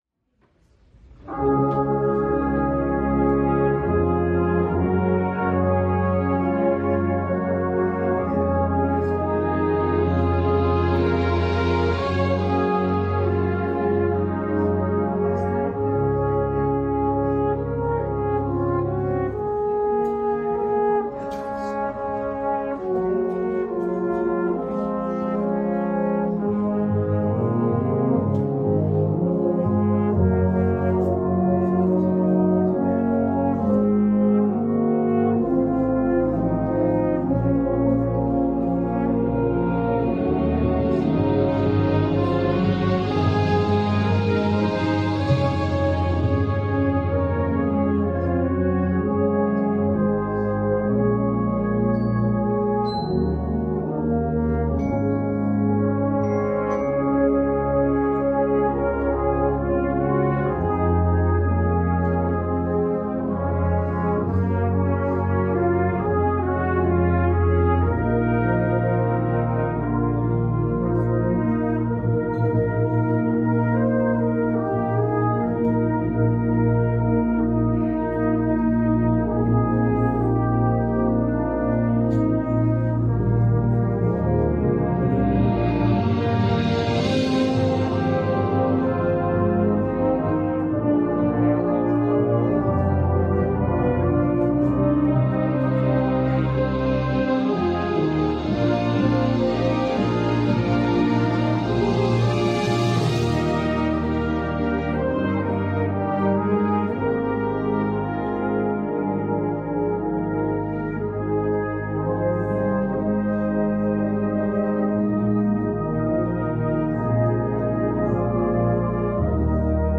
(Hymn Tune Arrangement)